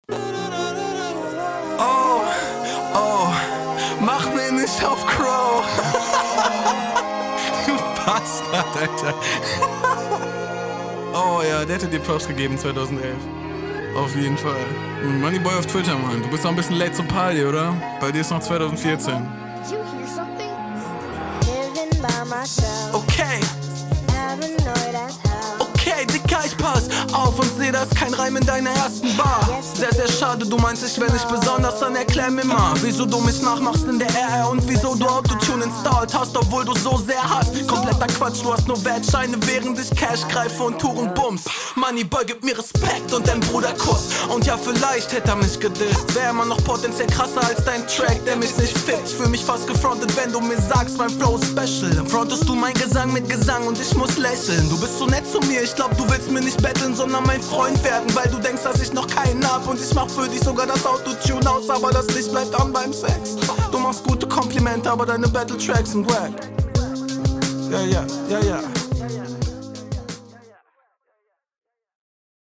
Sehr cooler Konter, klingt geiler, ballert mehr, Lines nett gekontert und noch n paar seitenhiebe …